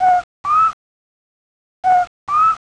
El puertorriqueño más conocido en Puerto Rico mide menos de pulgada y media de grande (unos 36mm) y tiene la voz más alta de la Isla.
coqui1.wav